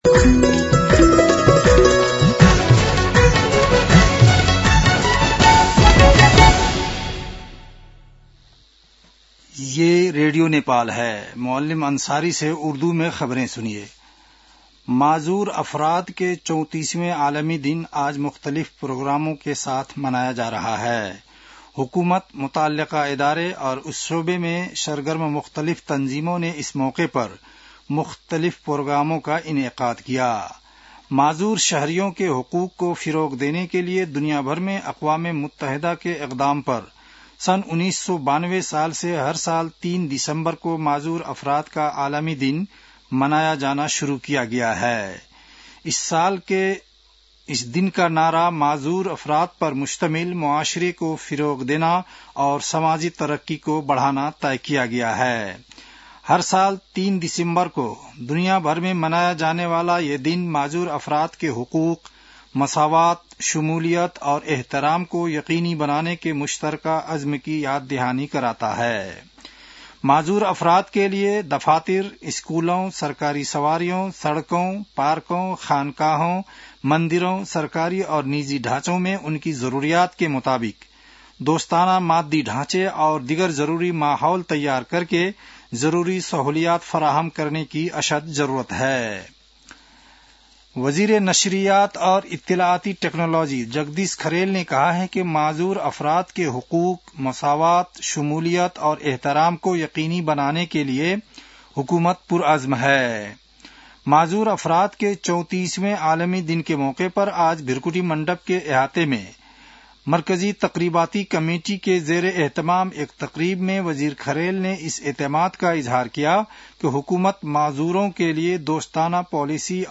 उर्दु भाषामा समाचार : १७ मंसिर , २०८२